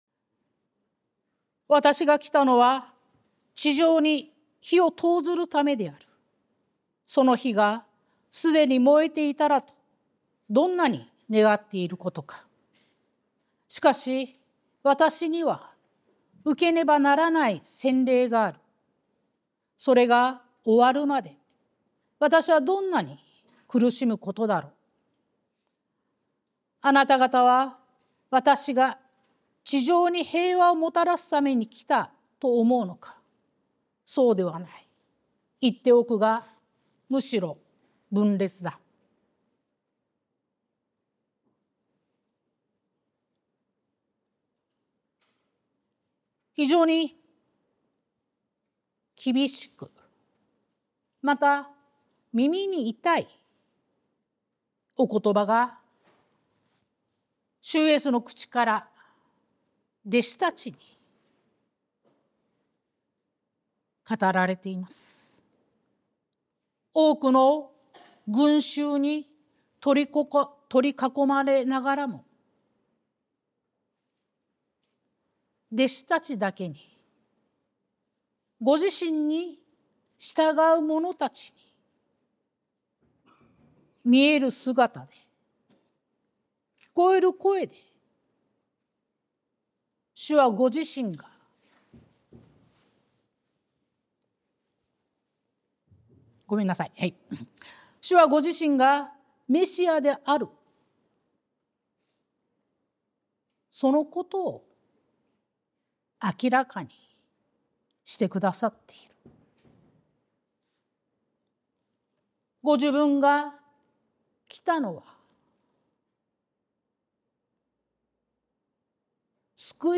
sermon-2025-02-16